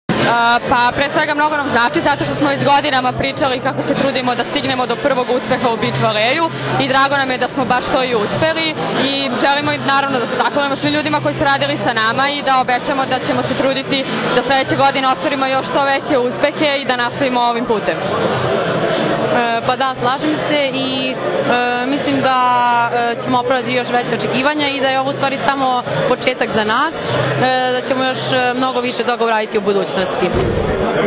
U beogradskom hotelu Metropol danas je održan tradicionalni Novogodišnji koktel Odbojkaškog saveza Srbije, na kojem su podeljenje nagrade najboljim pojedincima i trofeji “Odbojka spaja”.
IZJAVE